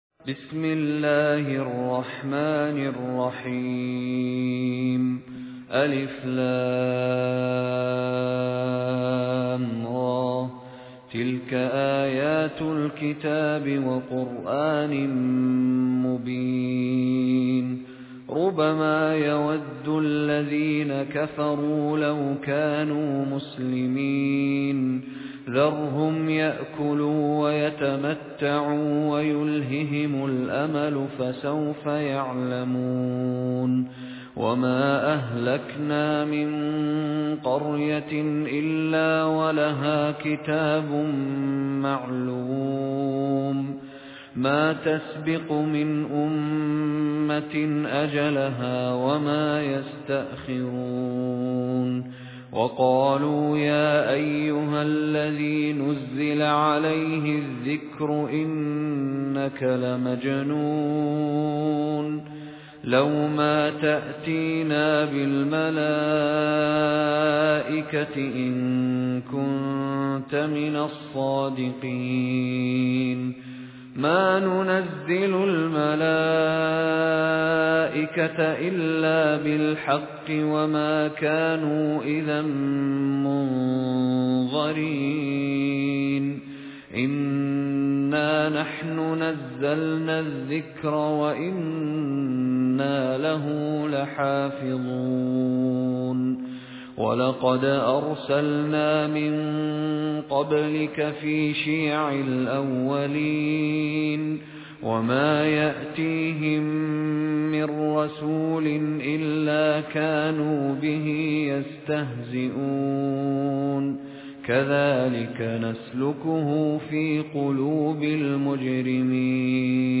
استمع أو حمل سُورَةُ الحِجۡرِ بصوت الشيخ مشاري راشد العفاسي بجودة عالية MP3.
سُورَةُ الحِجۡرِ بصوت الشيخ مشاري راشد العفاسي